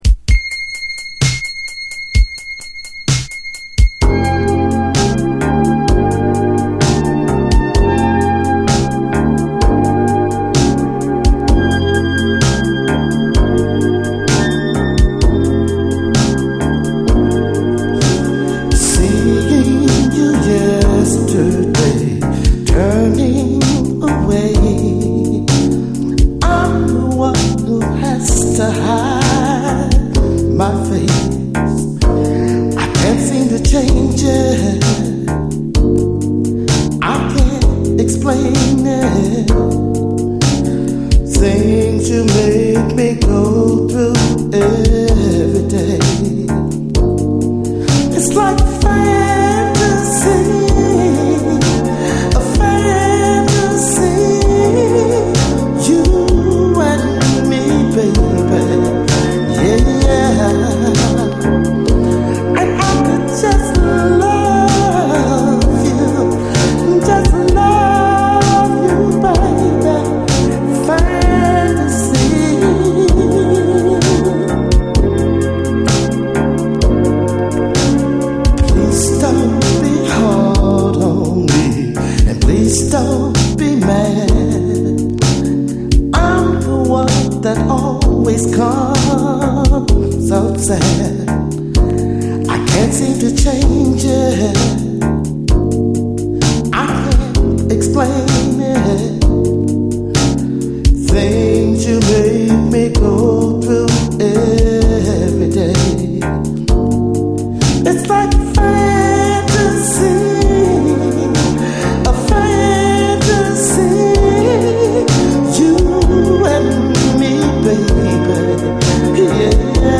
SOUL, 70's～ SOUL
マイナー盤でしかありえない、とにかく半端でない沈み込むようなレイドバック感がヤバい、白昼夢ダウンテンポ・モダン・ソウル！